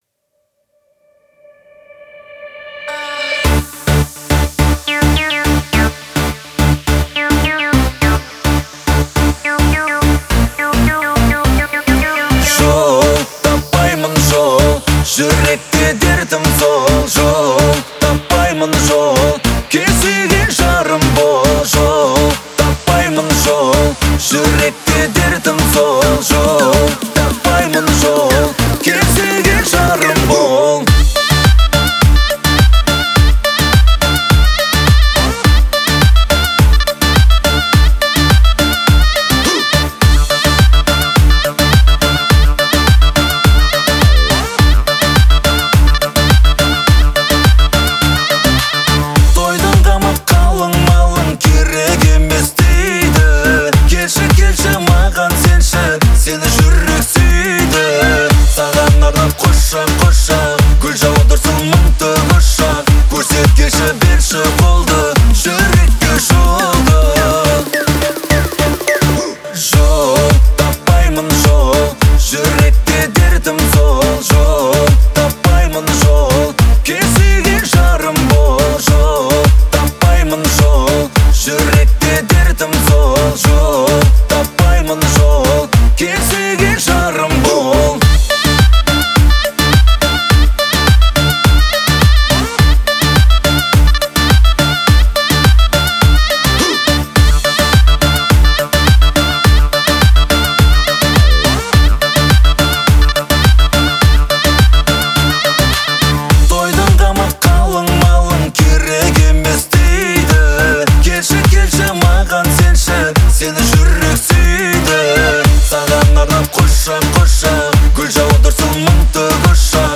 это яркий образец казахской поп-музыки